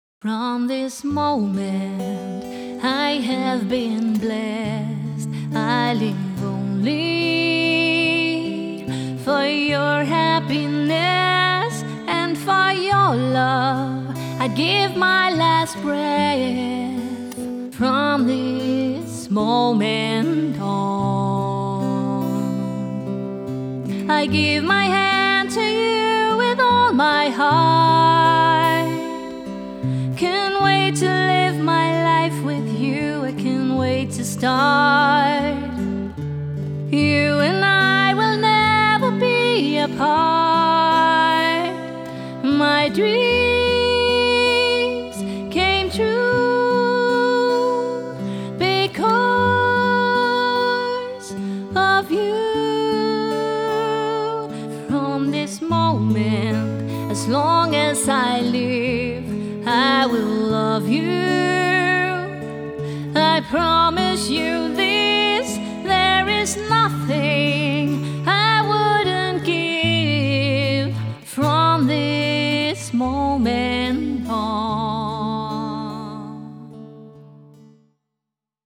Sangerinde og solomusiker (sang og guitar)
Smukt, akustisk og rørende.
Jeg spiller guitar til og medbringer selv udstyr.